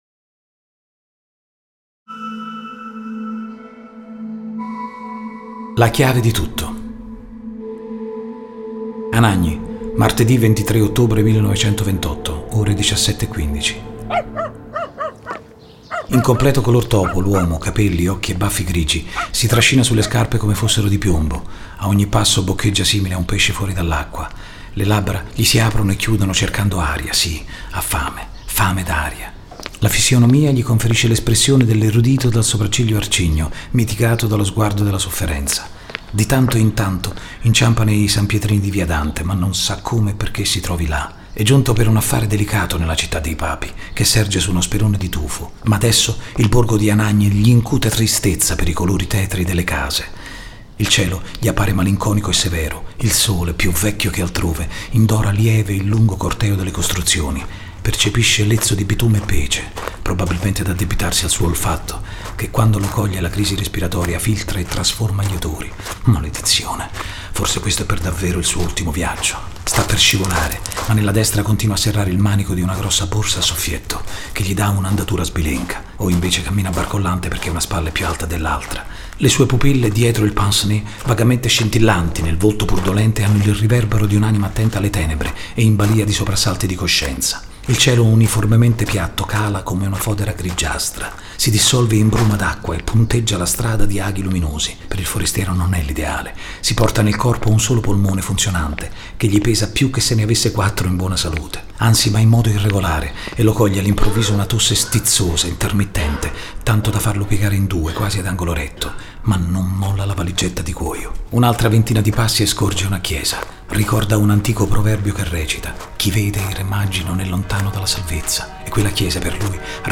Interpretazione e sonorizzazione